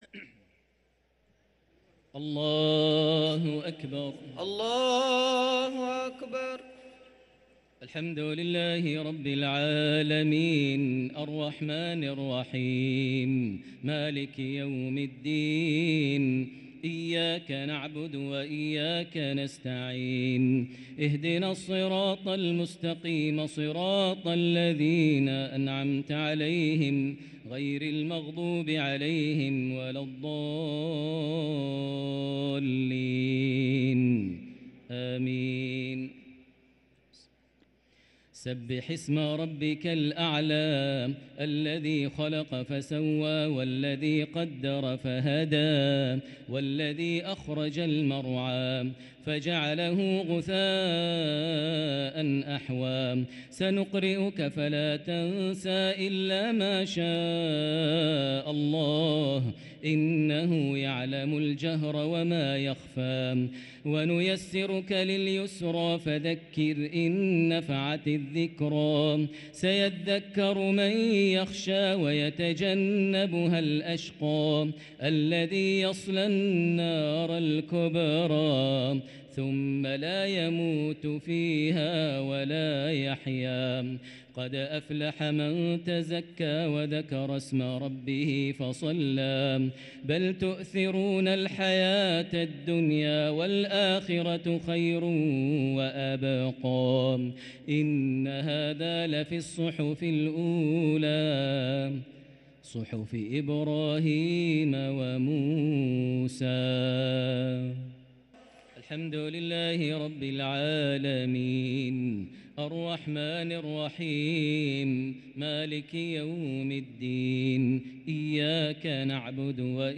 الشفع و الوتر ليلة 8 رمضان 1444هـ > تراويح 1444هـ > التراويح - تلاوات ماهر المعيقلي